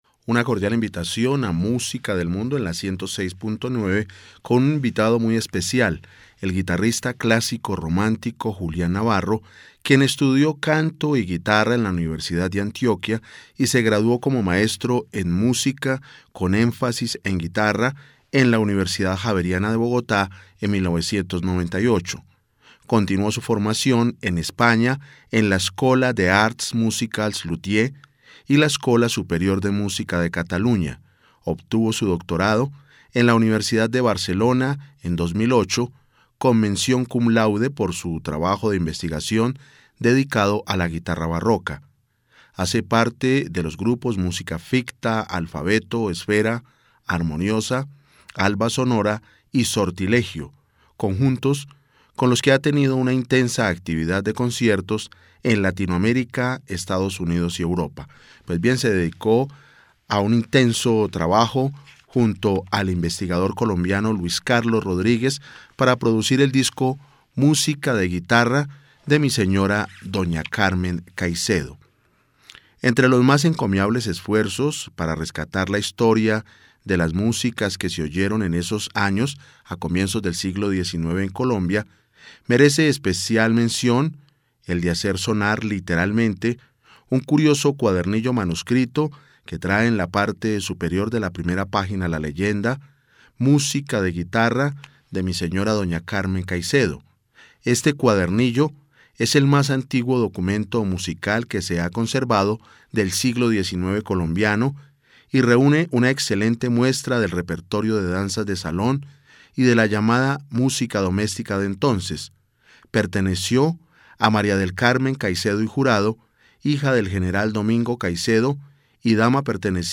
GUITARRISTA